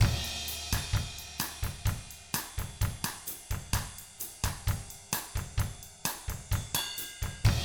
129BOSSAT1-L.wav